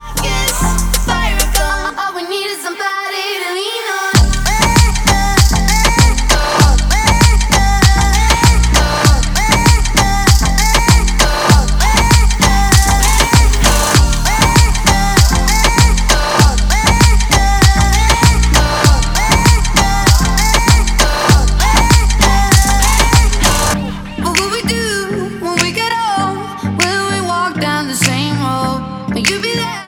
• trap,, dancehall